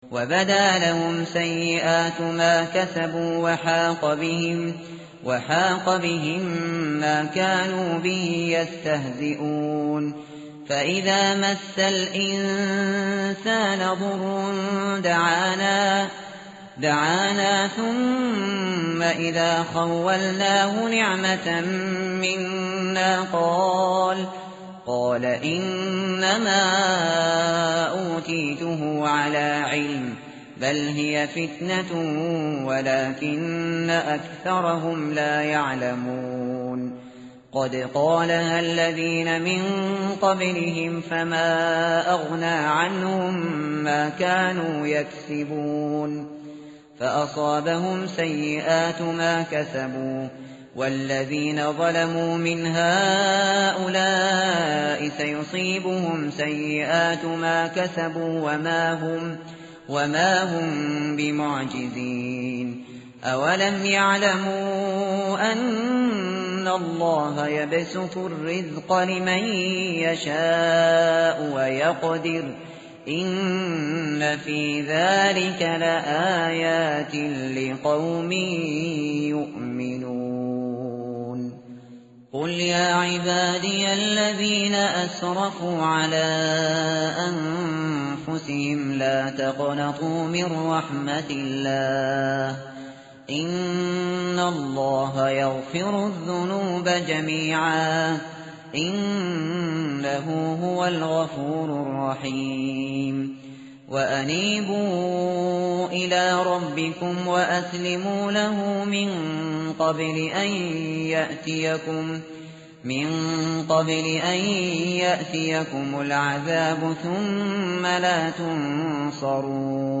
tartil_shateri_page_464.mp3